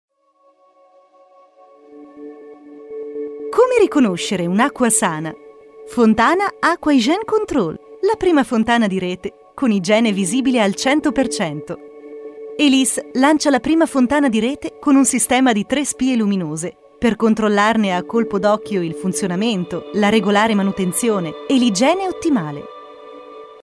Voce versatile per voiceovers, radio show, pubblicità e messaggi di segreteria telefonica
Sprechprobe: Industrie (Muttersprache):
Italian Voice Over Talent & Radio Personality for narrations, advertising, documentaries and phone messages